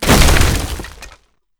WoodenCrate_Break.wav